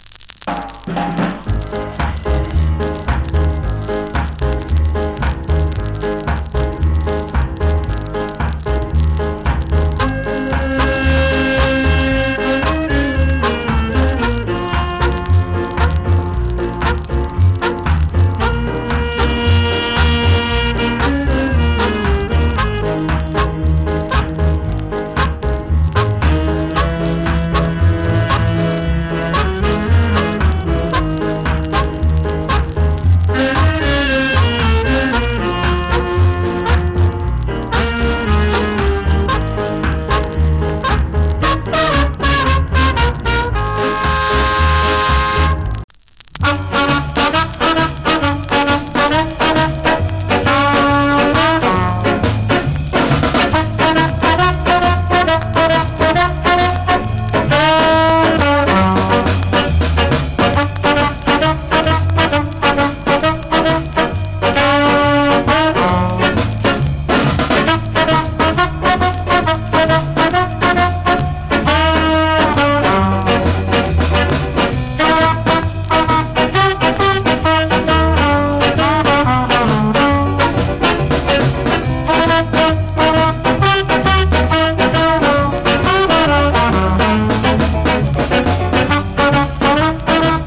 チリ、パチノイズ有り。
MELLOW INSTRUMENTAL !!